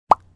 pop.ogg